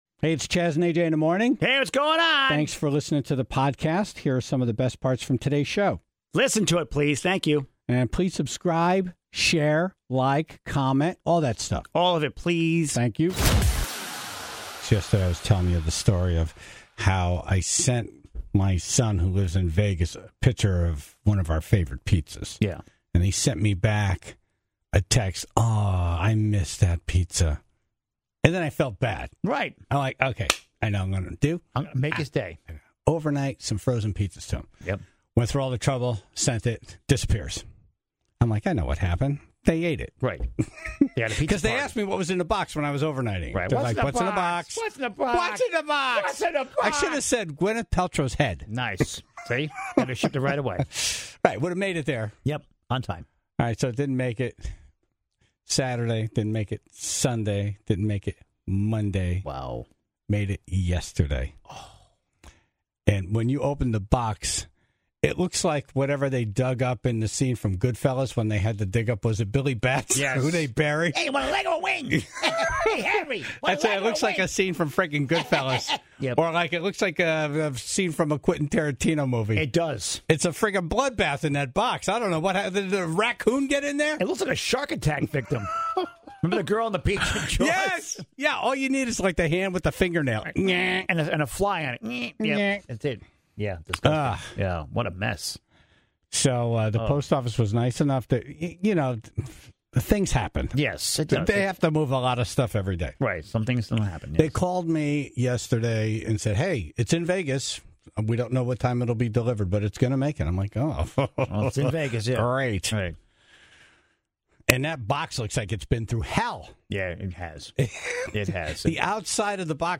the Tribe called in with multiple pieces of marital advice.